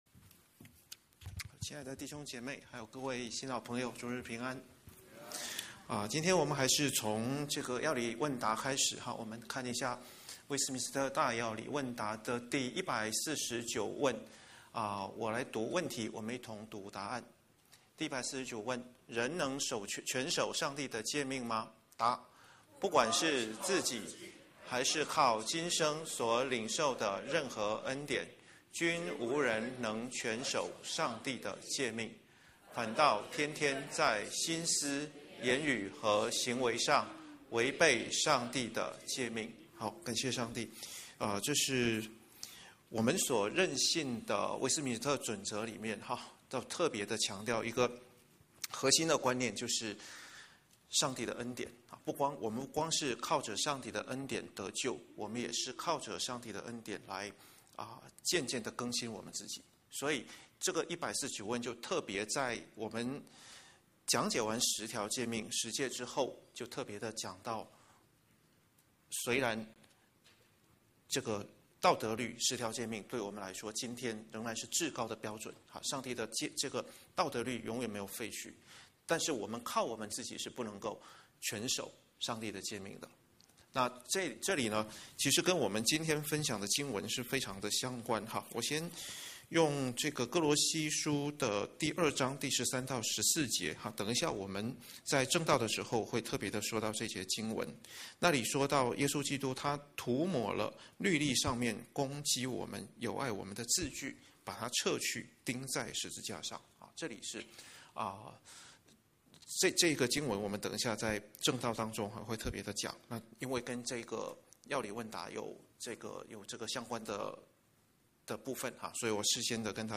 主日證道